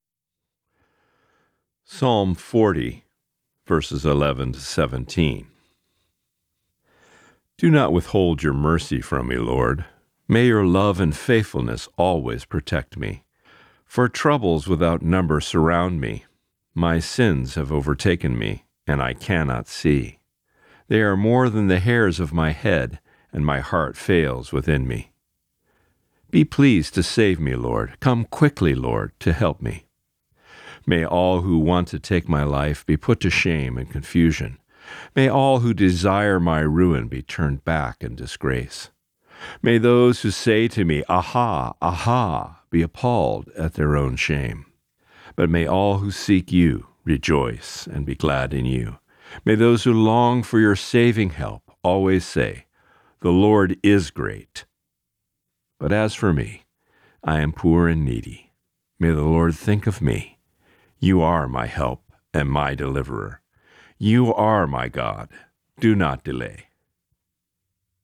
Reading: Psalm 40:11-17